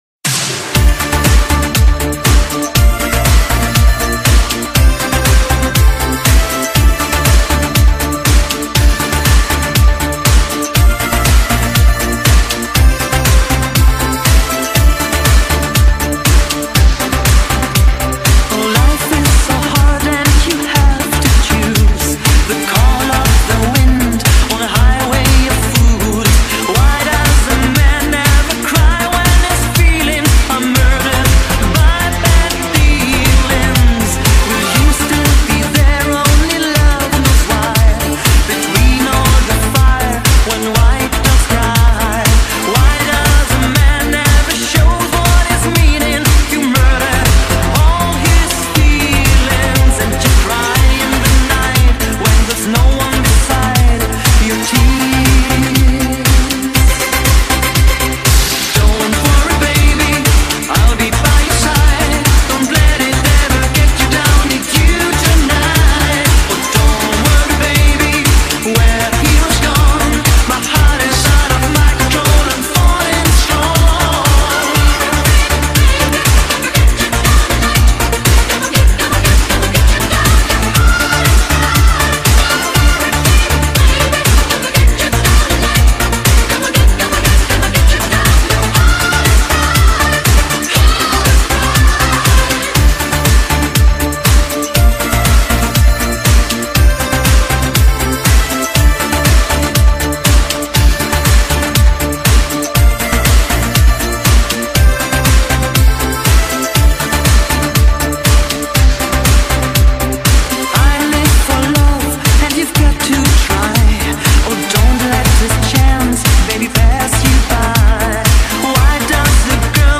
Synthpop / Europop / 80s Pop